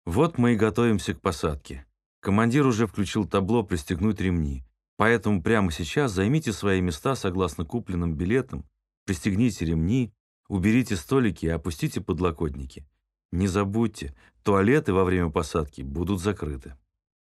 В самолётах «Победы» появилась новая озвучка: спортивного комментатора Дмитрия Губерниева заменил певец Леонид Агутин.
Замену скучного бубнежа в трубку интеркома, который никто не слушает, на PRAM-записи (pre-recorded announcements) голосами звёзд придумали для привлечения внимания клиентов к озвучиваемым на борту правилам компании и демонстрации аварийно-спасательного оборудования.
PRAM-11-Seatbelt-sign-is-on.mp3